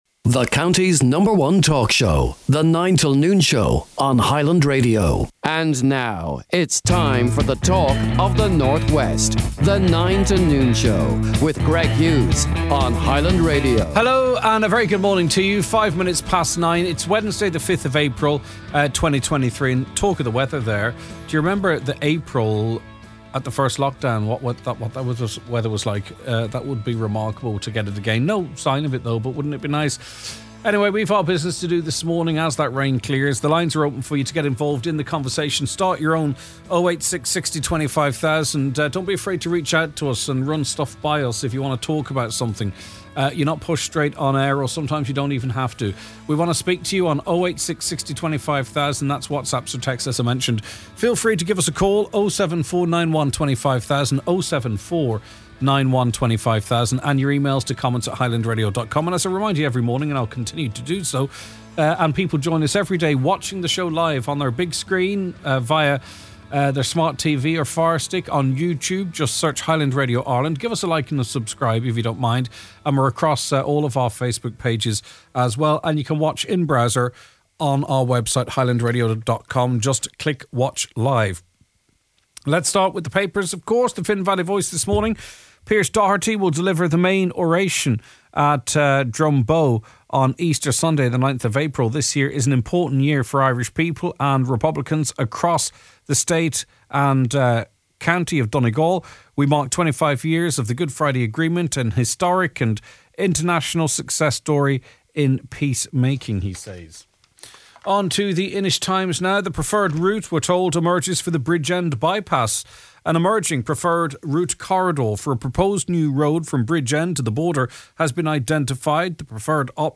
If you missed Wednesday’s live show, you can listen to the podcast bellow!